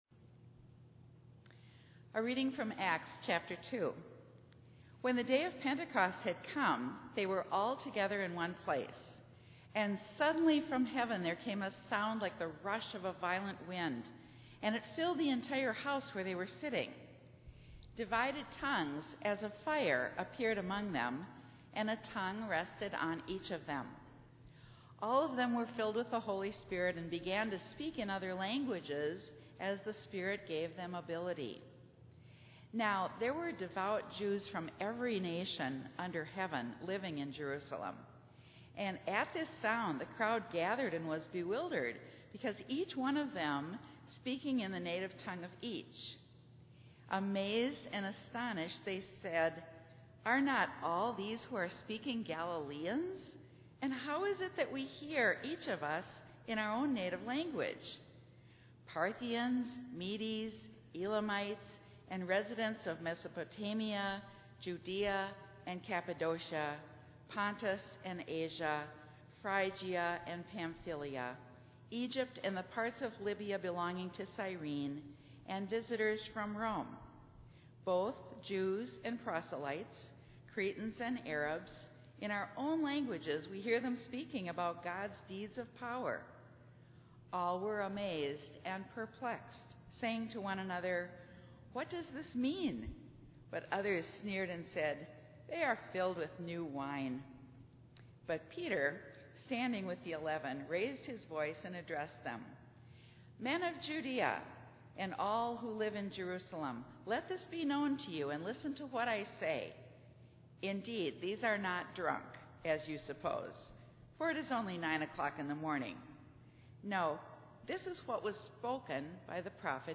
Minnetonka Livestream · Sunday, June 5, 2022 9:30 am
Sermon